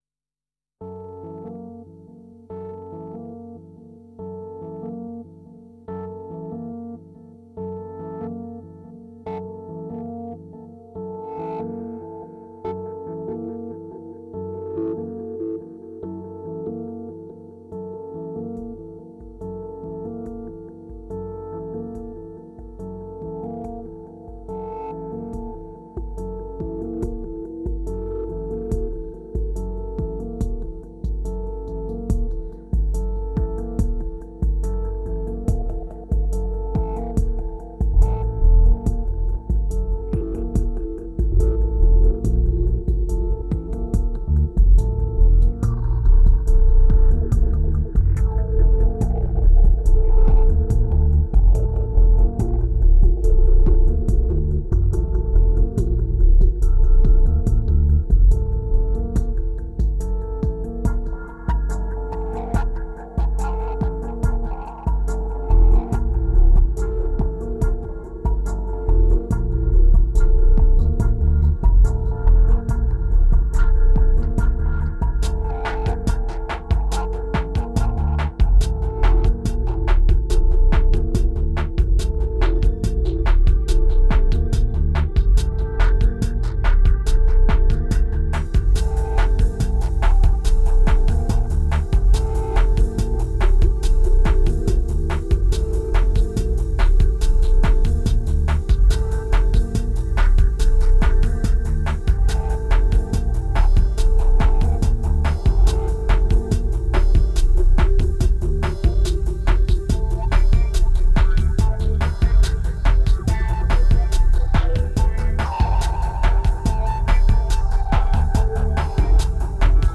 Drowning under massive FX, there still is a part of truth.